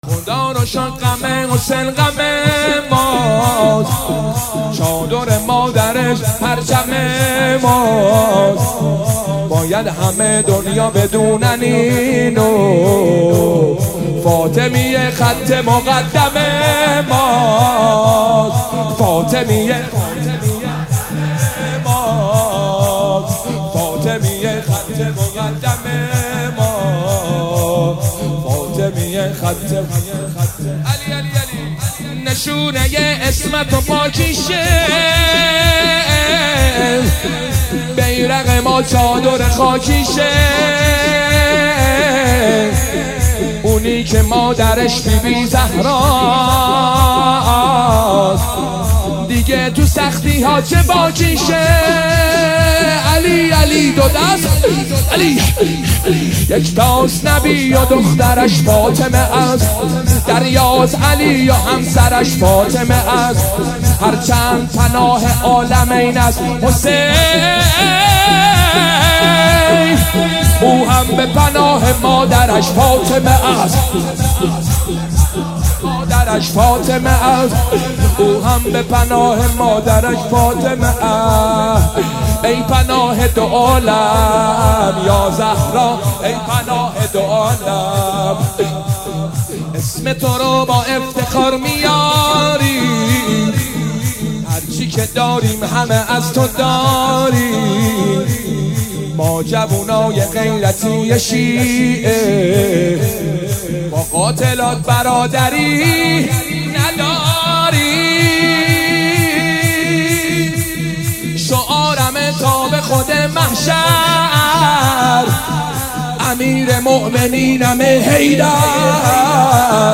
دانلود مداحی شور